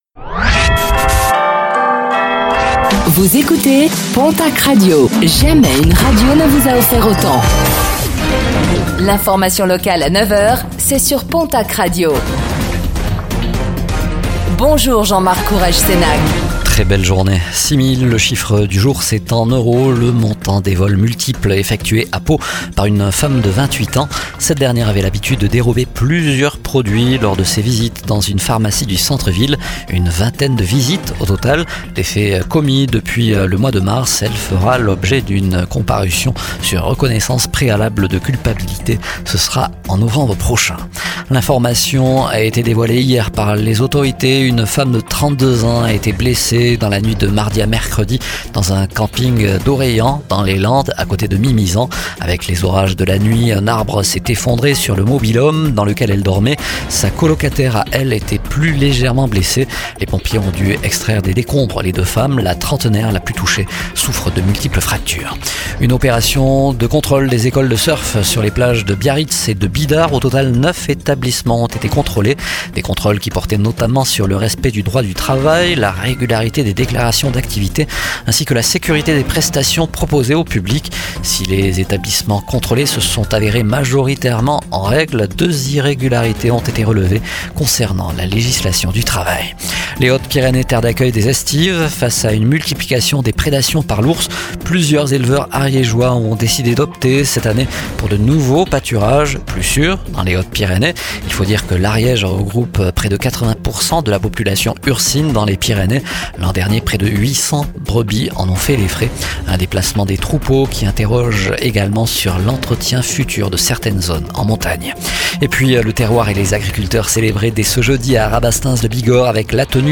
Infos | Jeudi 26 juin 2025